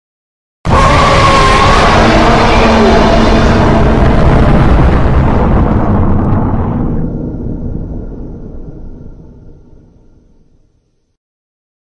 Ктулху внезапно вырывается из воды и атакует